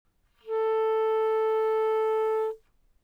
Bb Clarinet
BbClarA4.wav